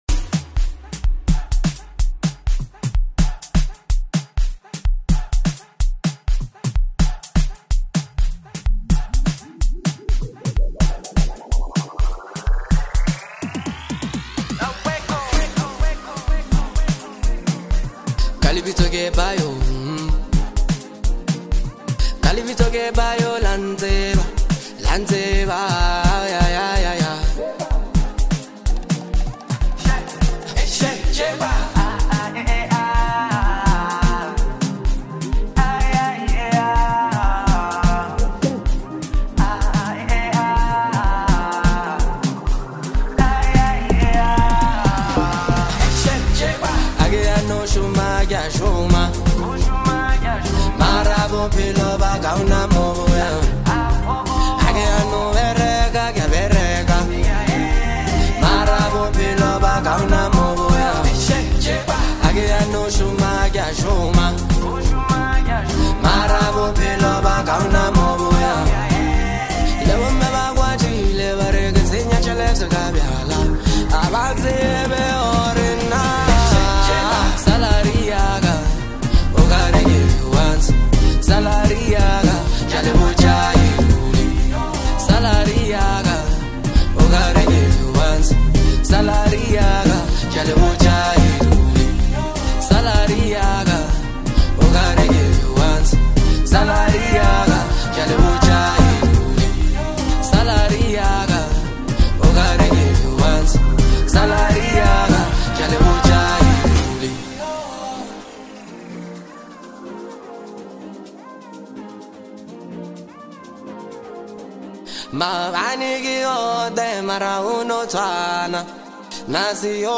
a deeply expressive and emotionally driven song